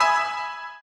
pianoBa_l.ogg